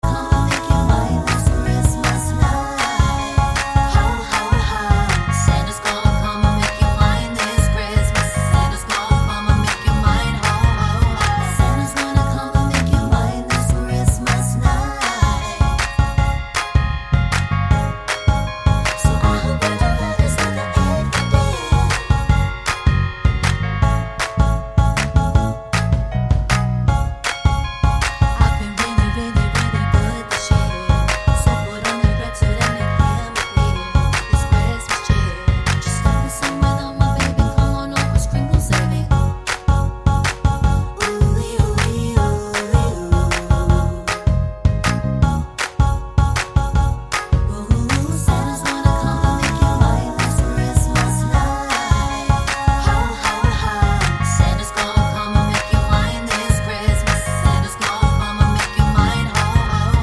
no Backing Vocals Christmas 3:20 Buy £1.50